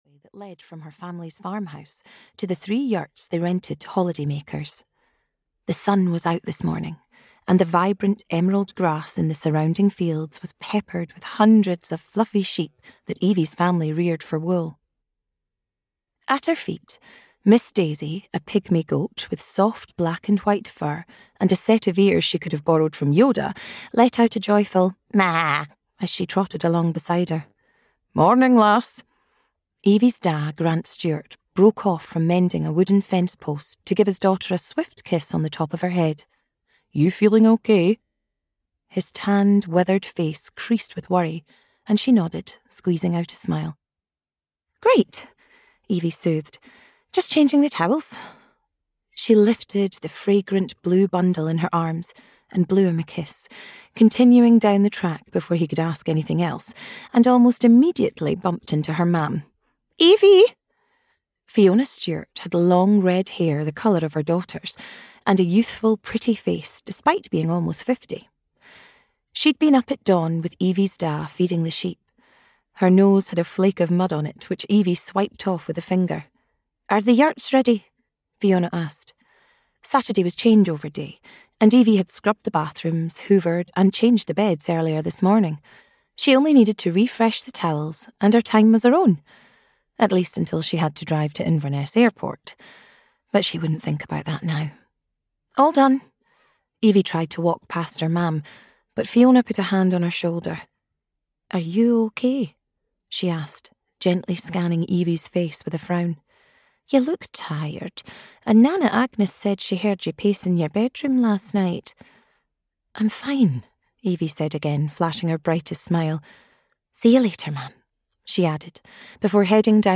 The Little Village of New Starts (EN) audiokniha
Ukázka z knihy